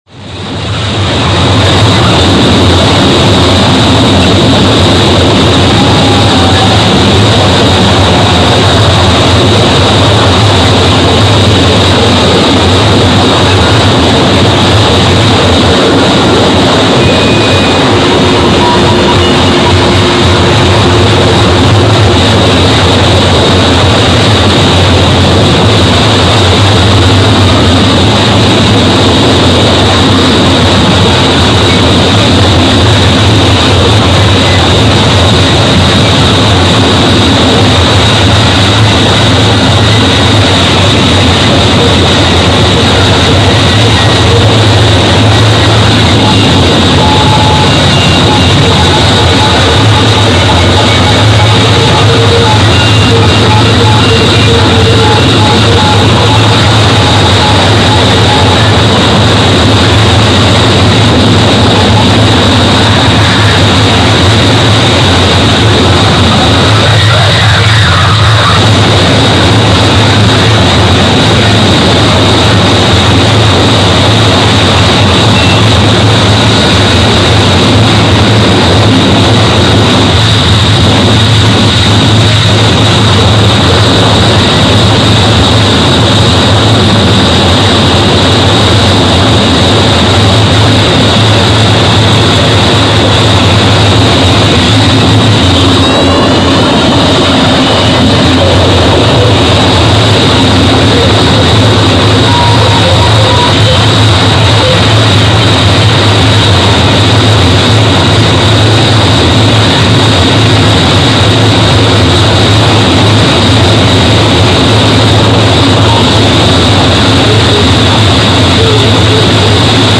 Live recordings from January 2006 by two Japanese masters.